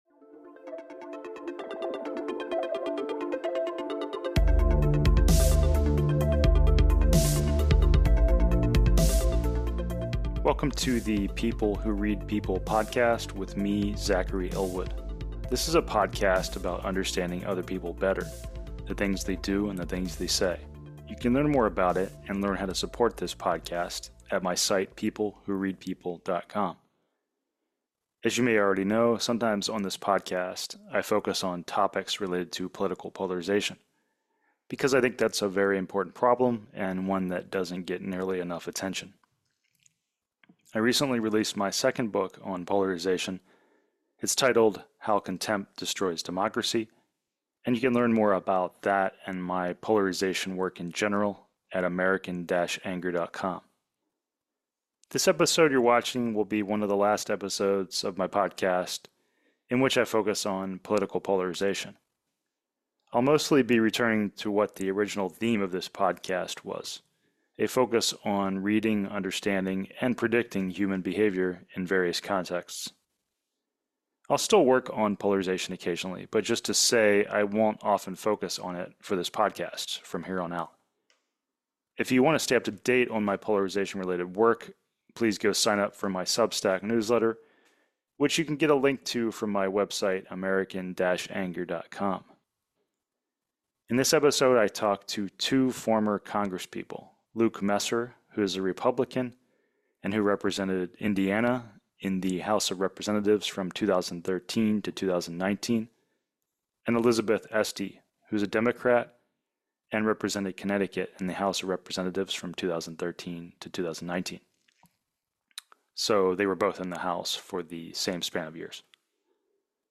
Two former congresspeople, a Democrat and a Republican, discuss toxic polarization from People Who Read People: A Behavior and Psychology Podcast | Podcast Episode on Podbay